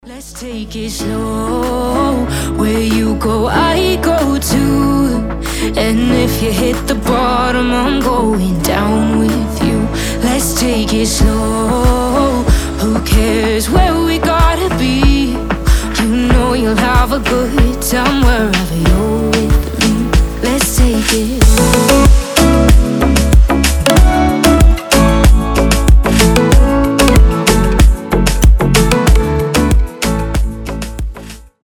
гитара
мелодичные
tropical house
нежные